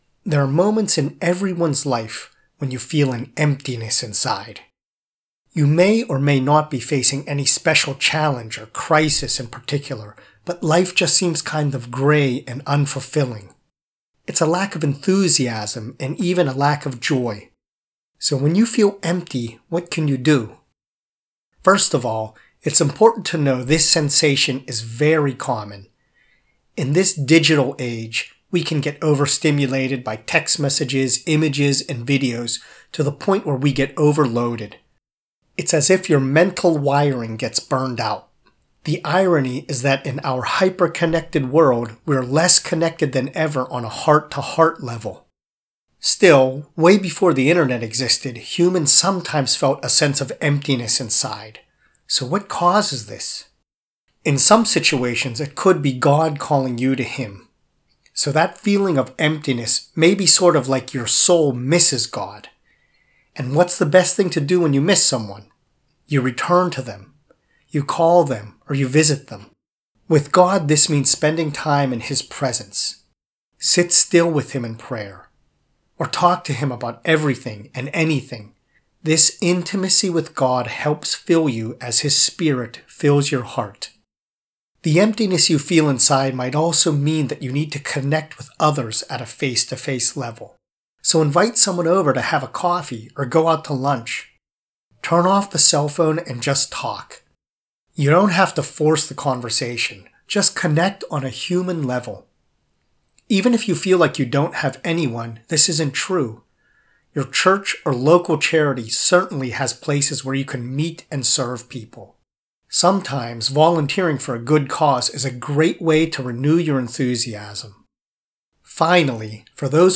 A message and prayer